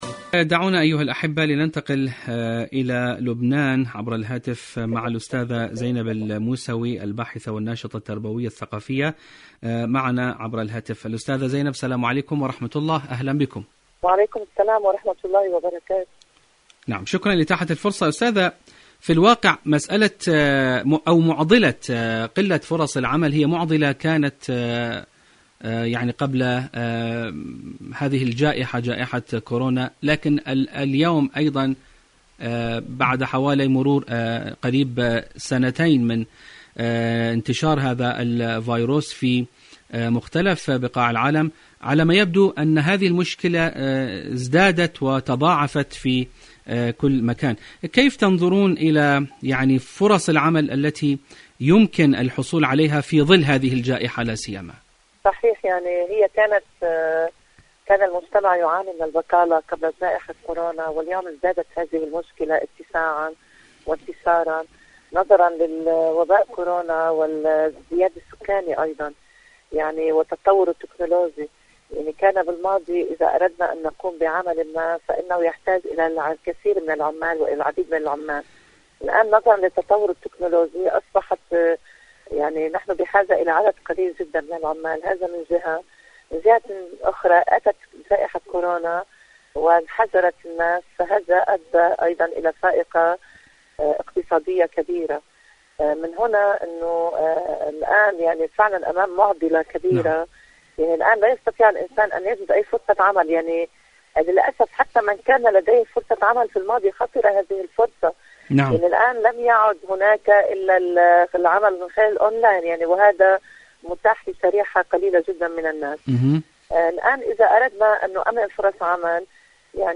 كيف أجد لنفسي فرصة عمل؟.. مقابلة
إذاعة طهران-دنيا الشباب: مقابلة إذاعية